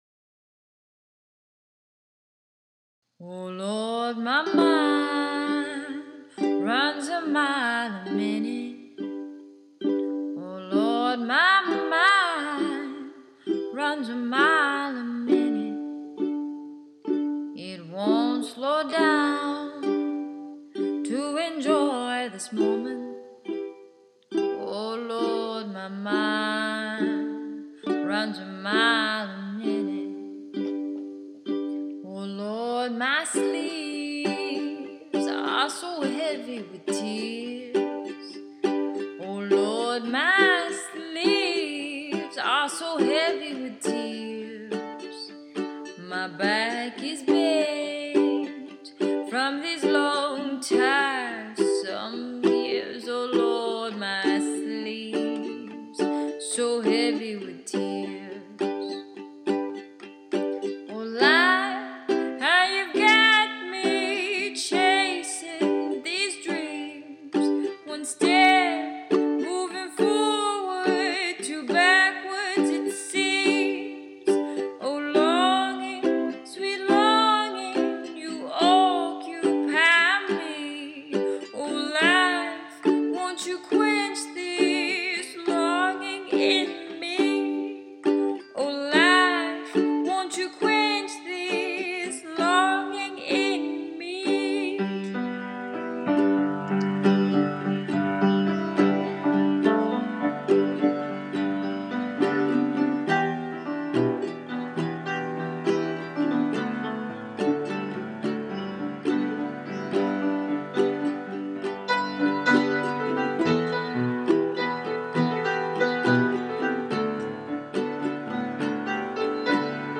(Blues)